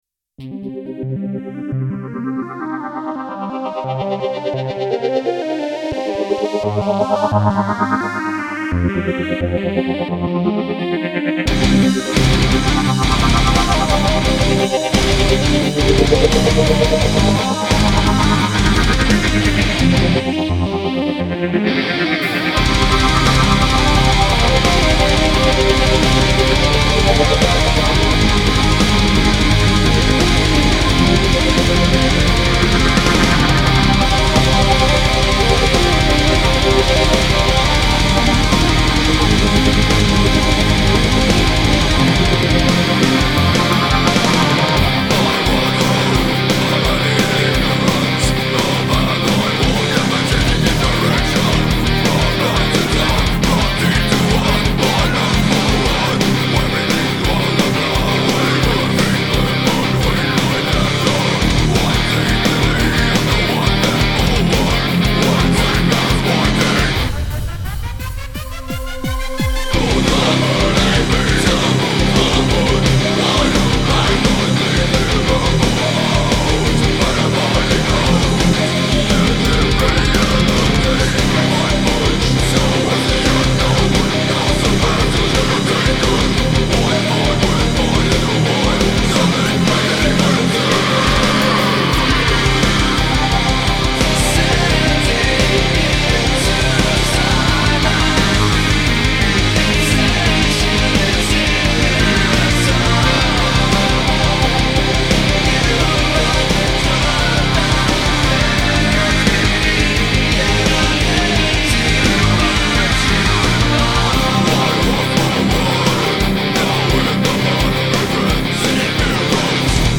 Melodic death metal band - what do you think?
Guitars are EMGs double tracked through a 6505, V30s, single SM57 on cab
Vocals are all though an SM57
I like the guitar sound.
Voices and keyboards/samples are emphasized too much IMO... and that permanent effect on the non-clean vocals can be ok for some parts occasionally but throughout the entire song it just bugs me.
Right now they sound just like a "backing track" to vocals/keyboards/samples.
It pushes the drums and guitar back and makes them sound distant.
Sounds like you have down tuned his voice in some parts as well.
The guitar is defiantly lost sometimes behind the synth.
IMO, the guitar is detailed and quite defined tone wise but is lacking abit of POWER at the minute.
The song is quite cool and catchy, I think its something alot of people could get into easy!
The vocals were double tracked and a doubler was put on them too, which listening back does sound a bit too much.. I'll take the doubler off them and hopefully that should sound better.